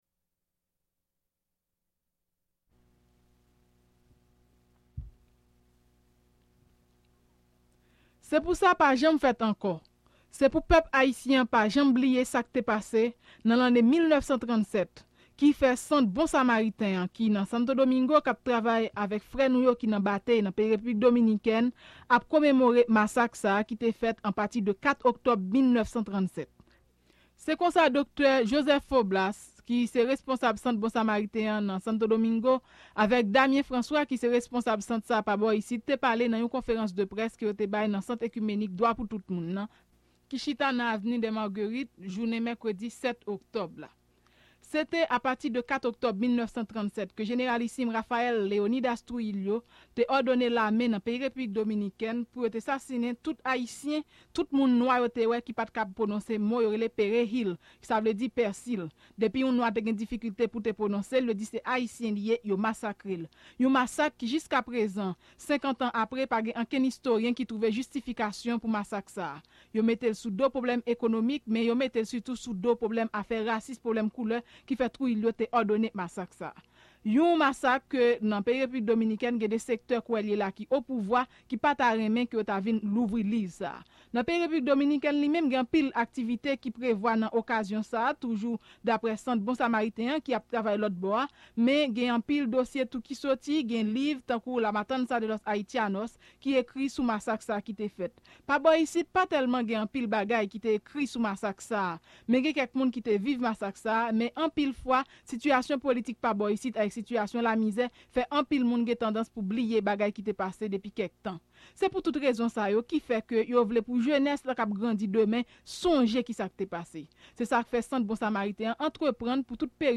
speak at a press conference about the history of the massacre and the present labor and human rights situation of Haitian migrants in the Dominican Republic.